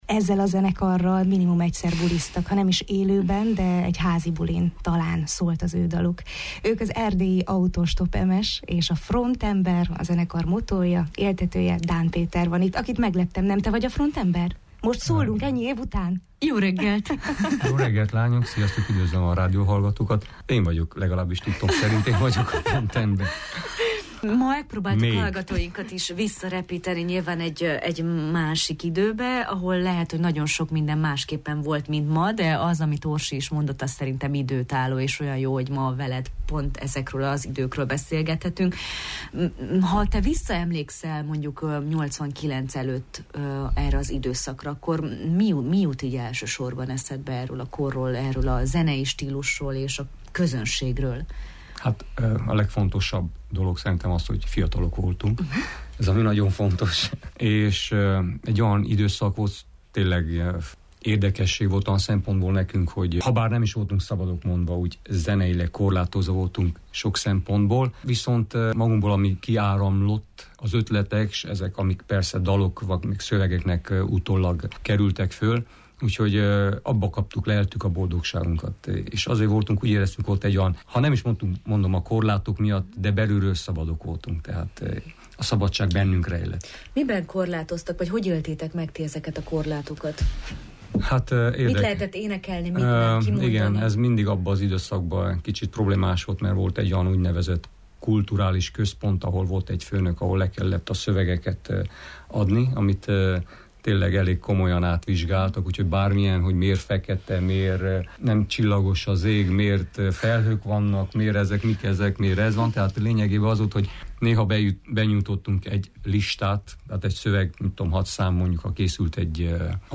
beszélgettünk a Jó reggelt, Erdély! műsorunkban: